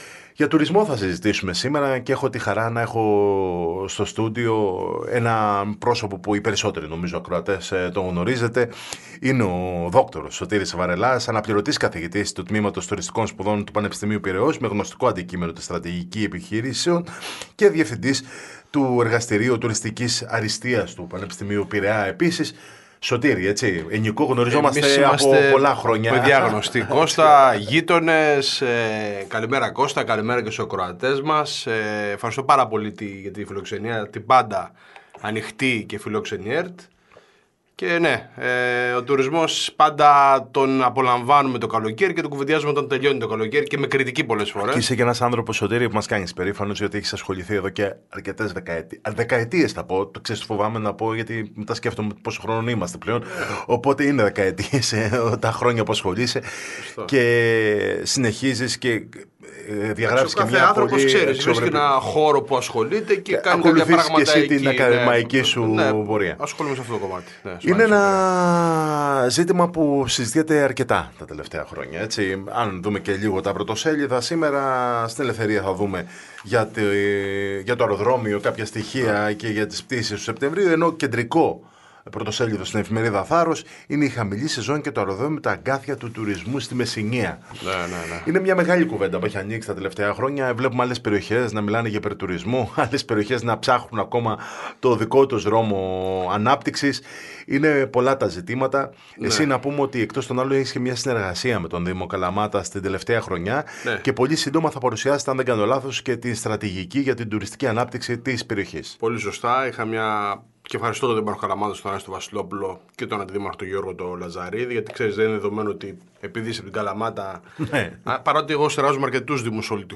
Συνέντευξη στο ραδιόφωνο της ΕΡΤ Καλαμάτας